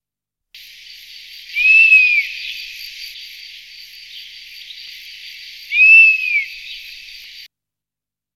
Bondrée apivore
Pernis apivorus
bondree.mp3